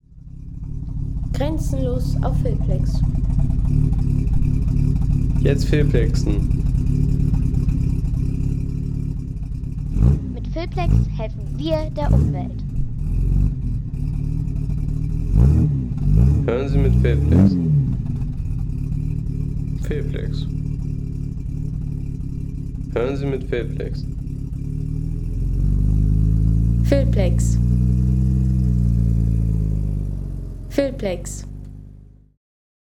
Jaguar XK 120 OTS SE - 1954 | Klassischer Autosound
Authentischer Jaguar XK 120 OTS SE Sound mit markantem Oldtimer-Motorcharakter.
Ein stilvoller Oldtimer-Sound mit blubberndem Motorcharakter für Filme, Dokus, Reisevideos und atmosphärisches Storytelling.